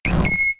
Cri de Mélofée K.O. dans Pokémon X et Y.